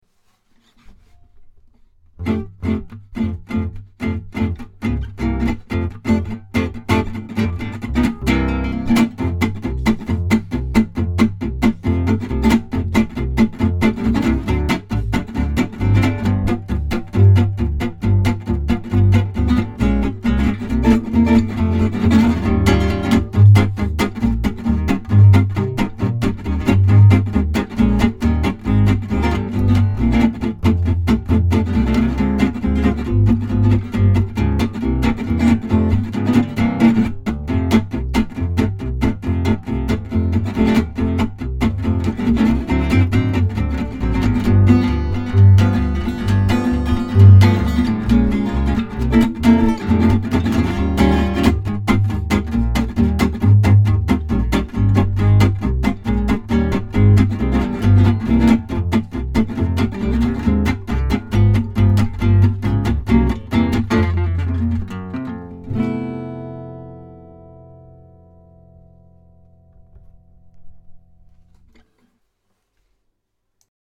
A bit of China Boy to demonstrate the lovely tone
Great tone and great playing!